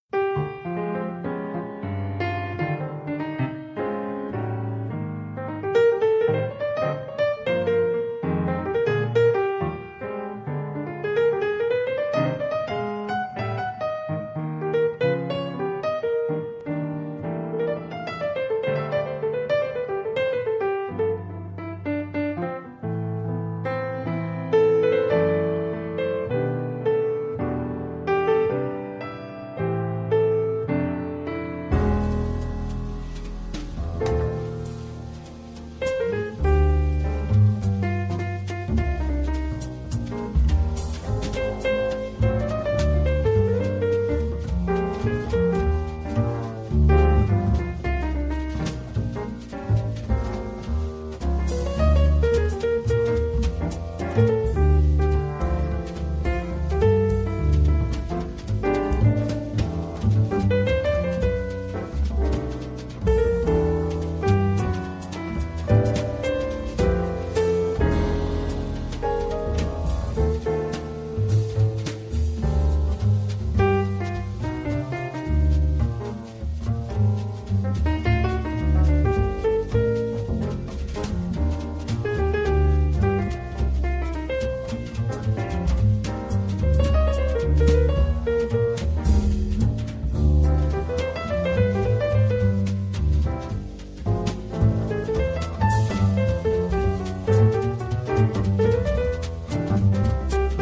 piano
contrabbasso
batteria